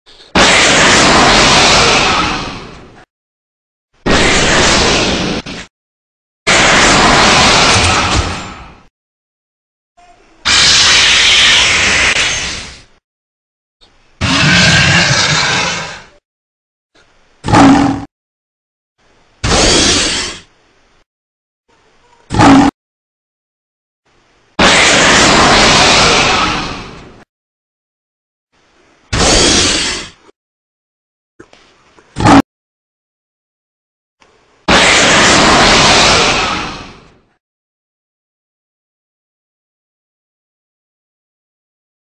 Golgolem_Roar.ogg